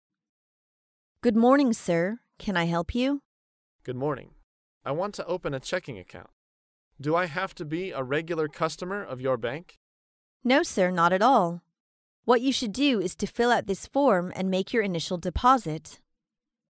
在线英语听力室高频英语口语对话 第419期:开立支票账户(1)的听力文件下载,《高频英语口语对话》栏目包含了日常生活中经常使用的英语情景对话，是学习英语口语，能够帮助英语爱好者在听英语对话的过程中，积累英语口语习语知识，提高英语听说水平，并通过栏目中的中英文字幕和音频MP3文件，提高英语语感。